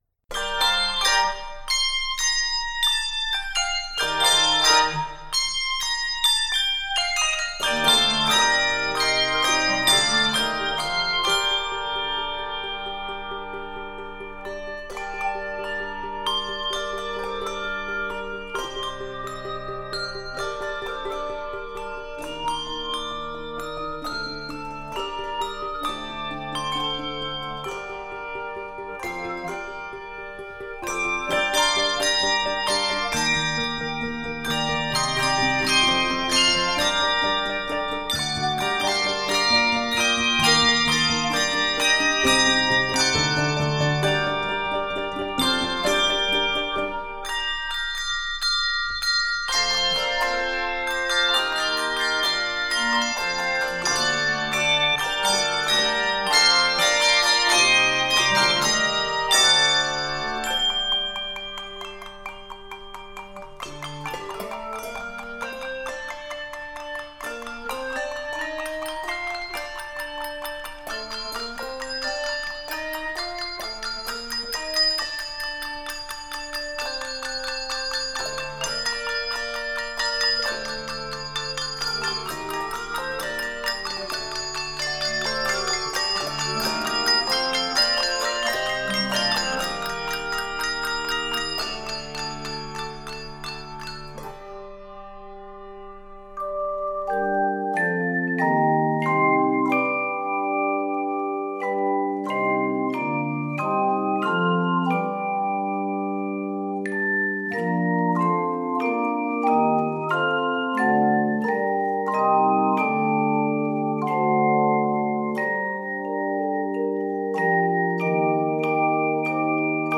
Rhythmic and upbeat